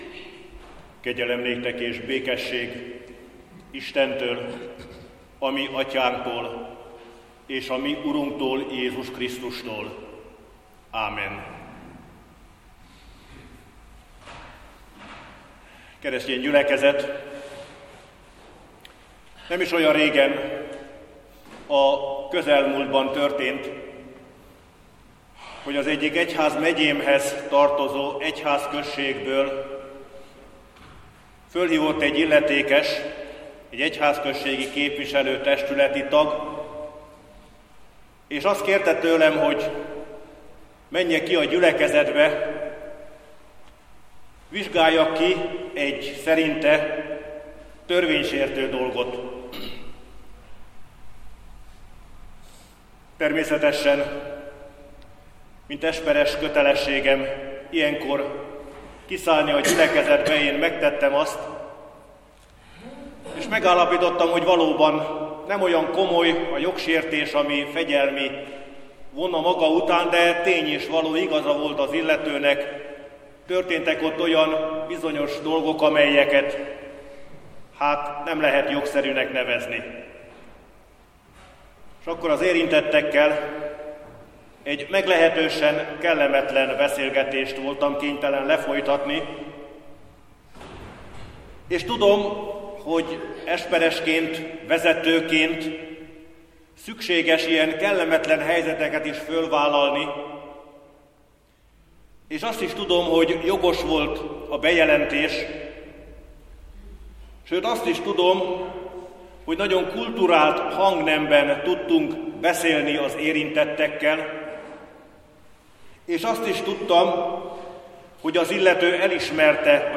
Ökumenikus imahét.